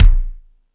Lotsa Kicks(39).wav